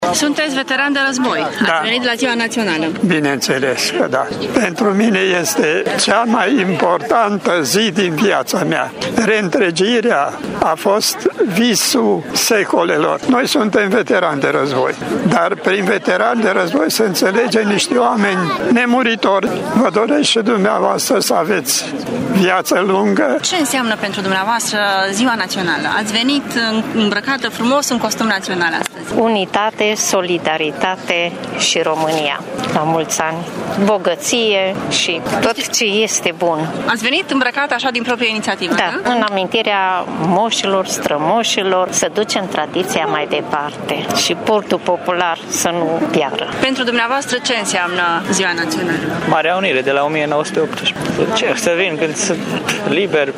Pentru târgumureșeni, participarea la ceremonialul de 1 Decembrie este o datorie și o onoare, mai ales pentru veteranii de război care privesc în mod cu totul special ziua de 1 Decembrie: